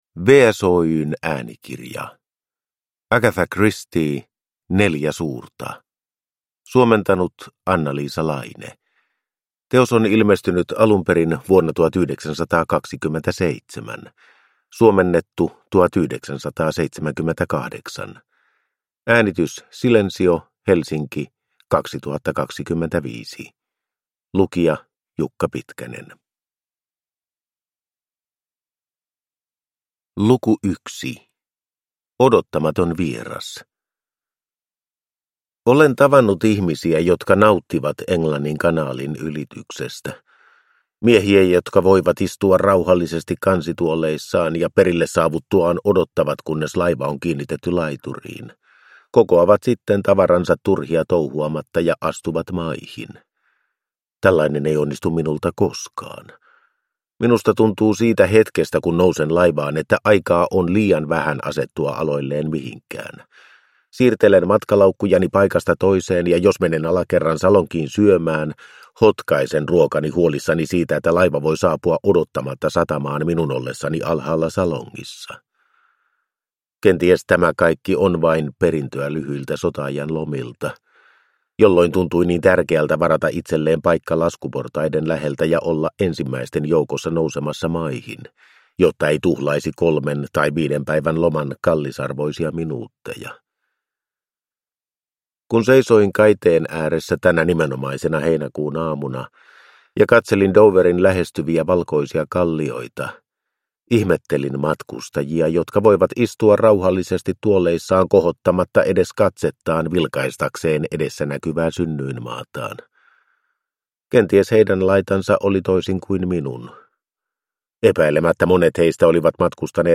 Neljä suurta (ljudbok) av Agatha Christie